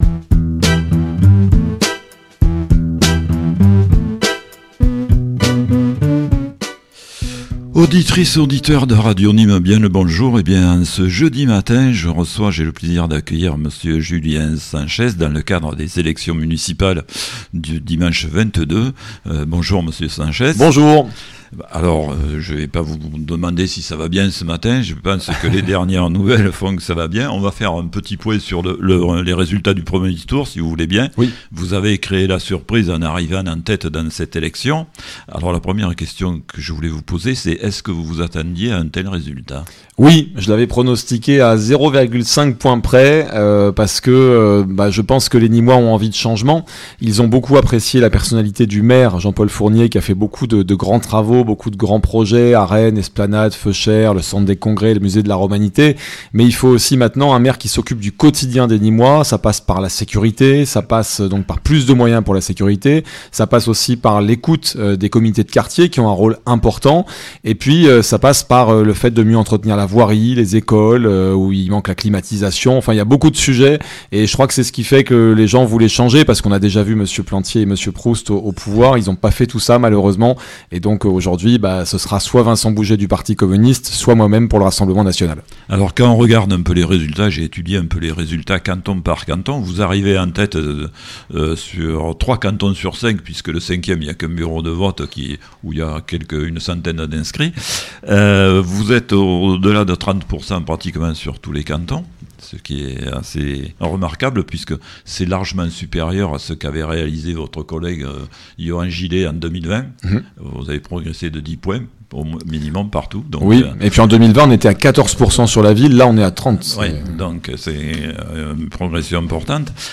Municipales 2026 - Entretien d'entre-deux-tours avec Julien SANCHEZ (Fier d'être nîmois) - EMISSION DU 19 MARS